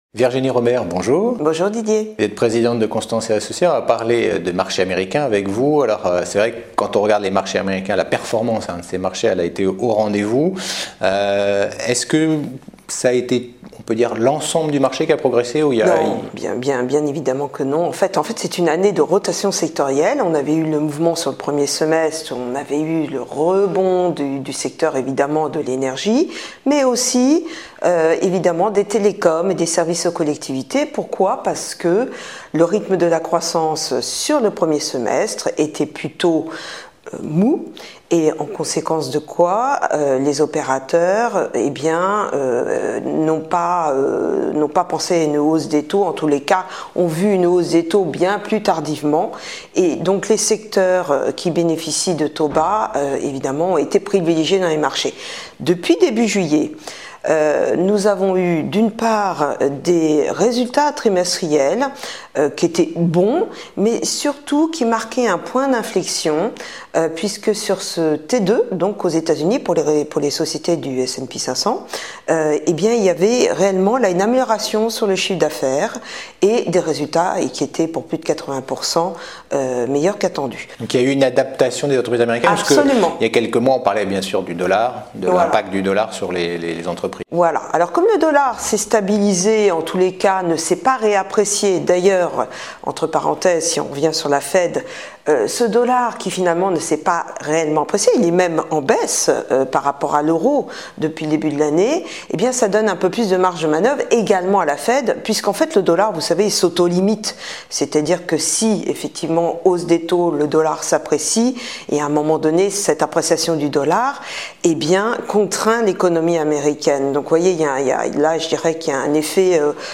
Mon invitée est